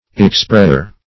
Search Result for " expressure" : The Collaborative International Dictionary of English v.0.48: Expressure \Ex*pres"sure\ (?;135), n. The act of expressing; expression; utterance; representation.
expressure.mp3